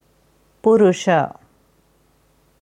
Sanskrit Purusha korrekte Aussprache anhören
Dieses Wort auf Devanagari schreibt man पुरुष, in der IAST Schreibweise puruṣa. Hier kannst du hören, wie man ganz korrekt Purusha ausspricht.